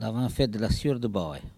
Elle provient de Saint-Urbain.
Locution ( parler, expression, langue,... )